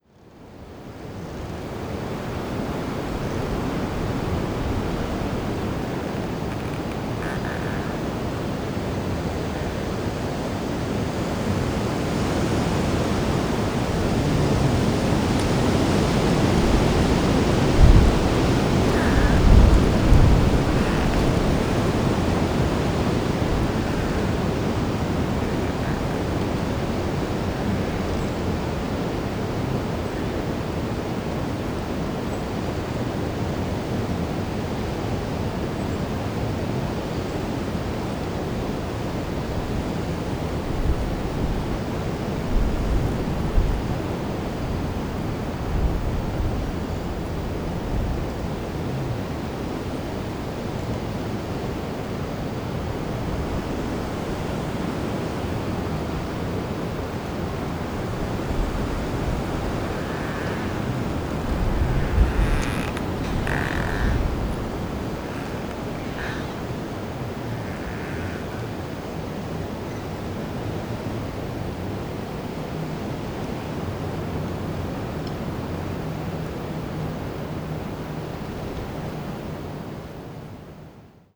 Genre : Soundscapes.